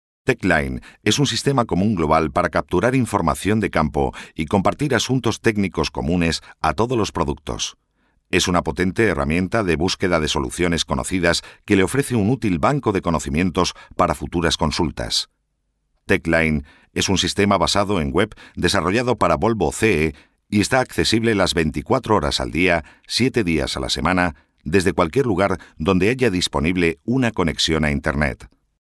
Sprechprobe: eLearning (Muttersprache):
mencion volvo.mp3